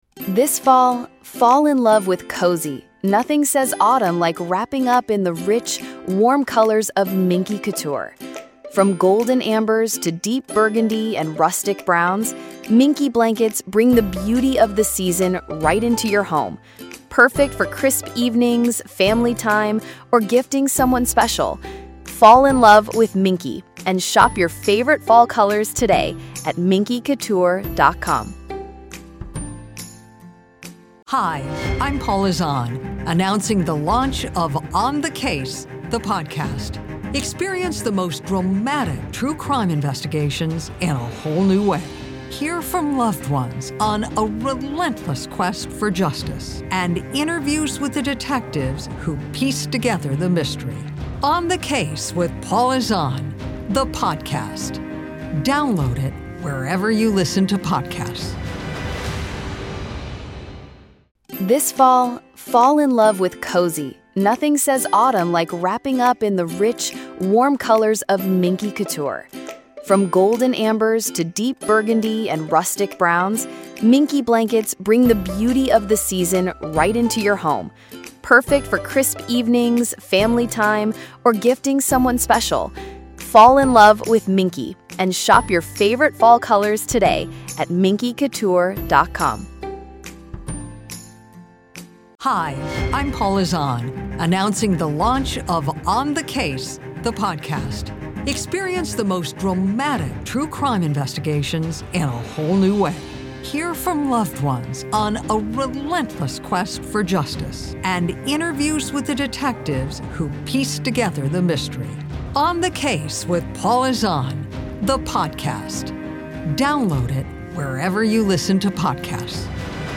If you’ve ever wondered whether those “weird little moments” matter, this conversation shows how they inform the long-term record—responsibly, without sensationalism.